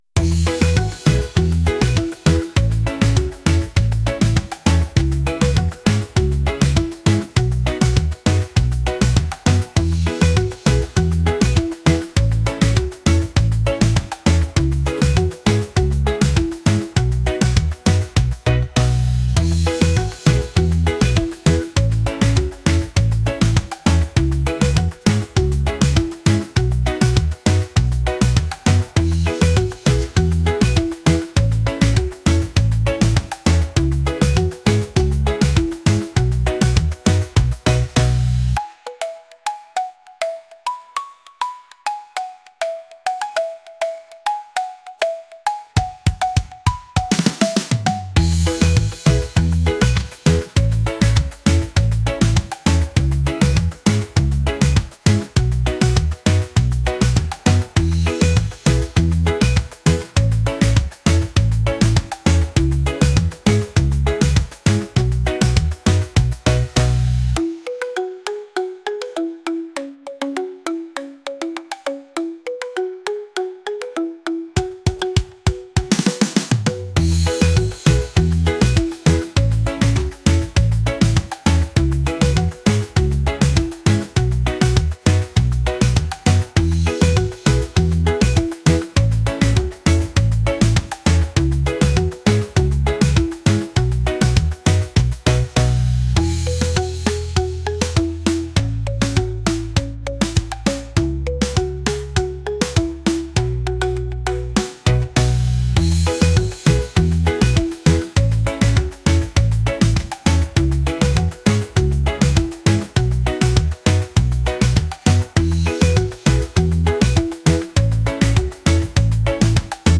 reggae | pop | world